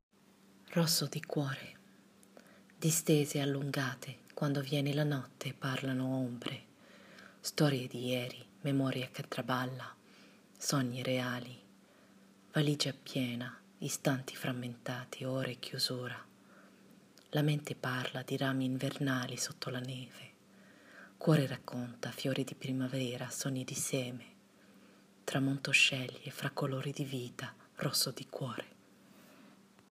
Lettura del poema: